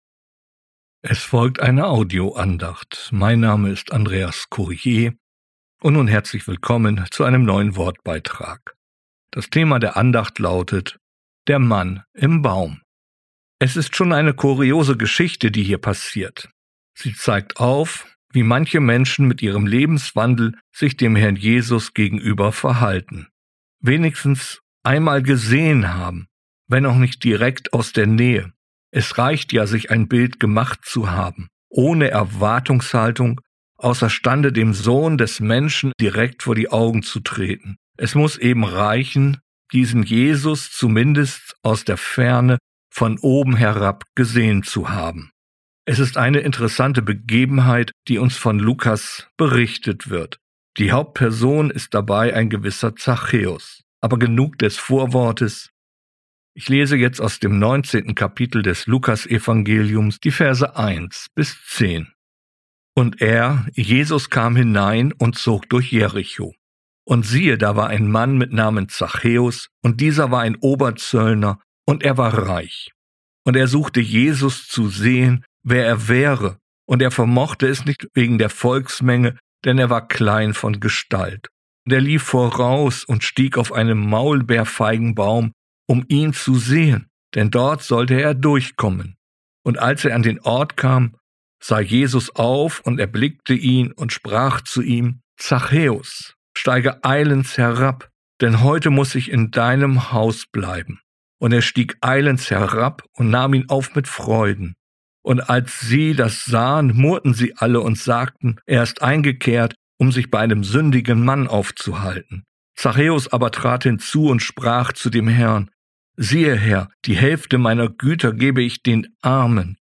Der Mann im Baum, eine Audioandacht